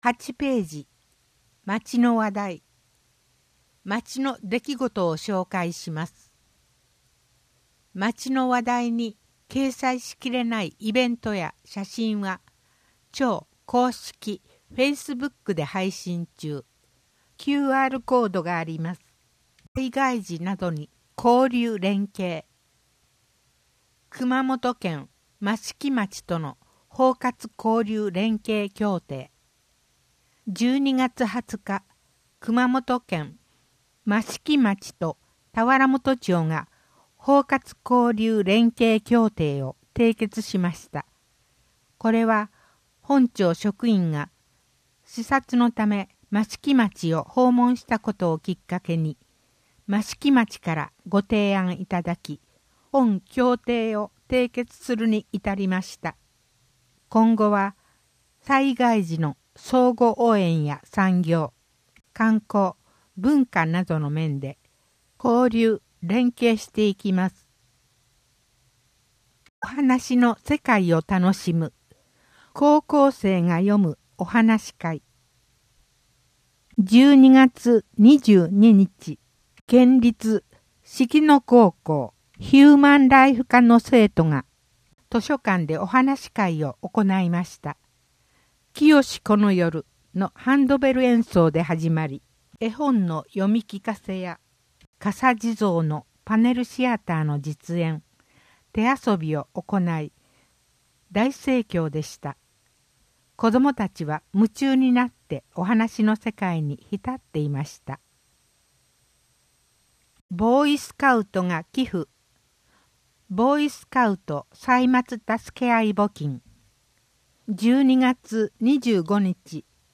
音訳広報たわらもと1ページ (音声ファイル: 973.8KB)